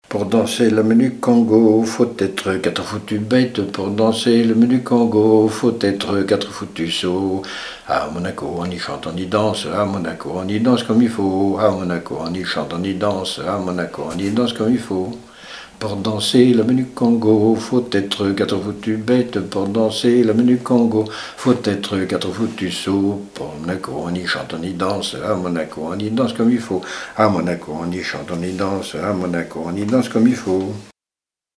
Mémoires et Patrimoines vivants - RaddO est une base de données d'archives iconographiques et sonores.
Chants brefs - A danser
Répertoire de chants brefs pour la danse
Pièce musicale inédite